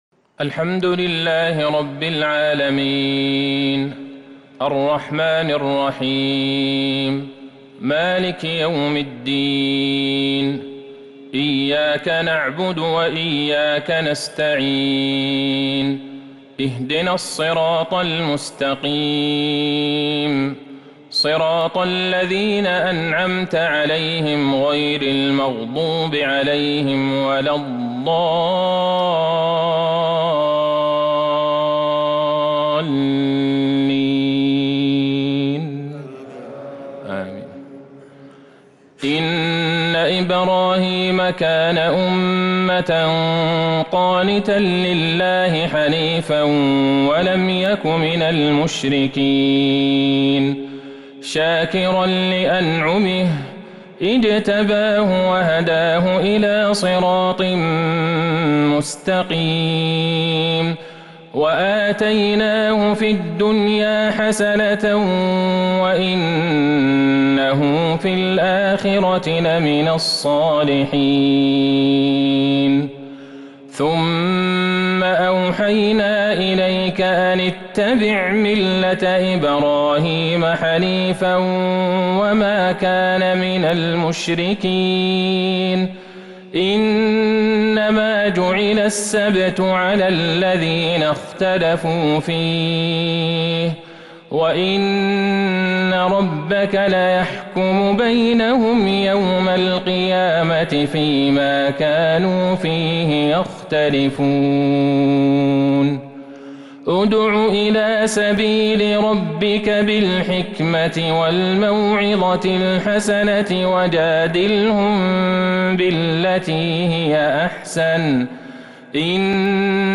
صلاة التراويح | ليلة ١٩ رمضان ١٤٤٢هـ | خواتيم النحل وفواتح الإسراء 1-52 | Taraweeh 19st night Ramadan 1442H > تراويح الحرم النبوي عام 1442 🕌 > التراويح - تلاوات الحرمين